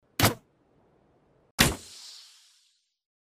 Звуки арбалета
Два выстрела из арбалета